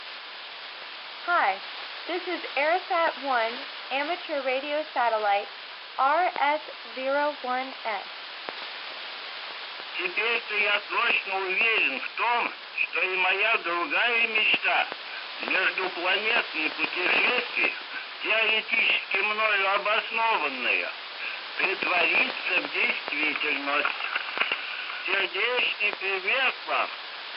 голос ARISS1